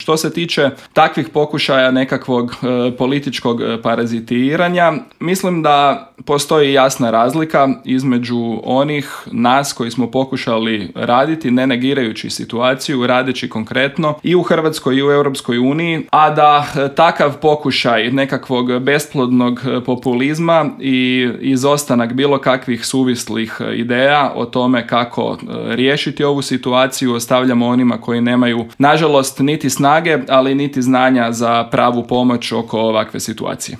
Više detalja o Paktu, kako će se odraziti na Hrvatsku, ali i o kritikama na postignuti dogovor u intervjuu Media servisa razgovarali smo s eurozastupnikom iz redova HDZ-a, odnosno EPP-a Karlom Resslerom.